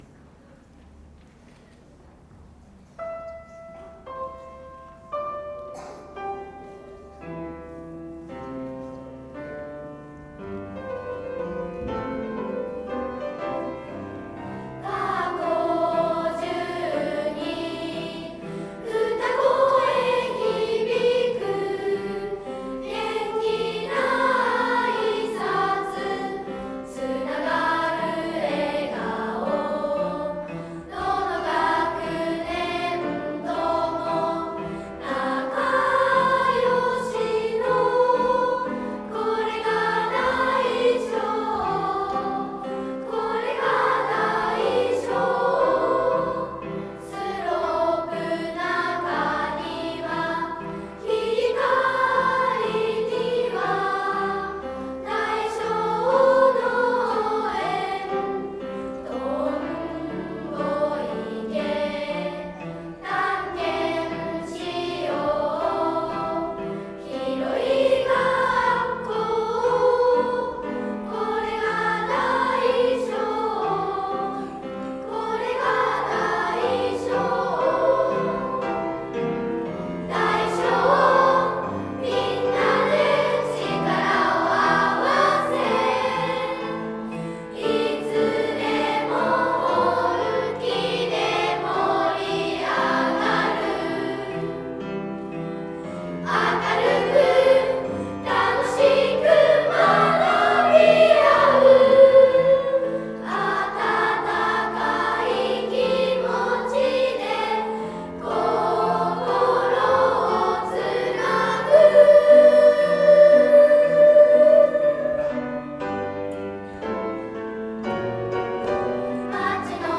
愛唱歌 いいとこたくさんみんなの台小
いいとこたくさんみんなの台小 リニューアル版（中休み合唱団収録）